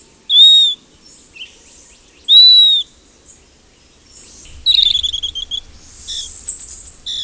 Jui durante el día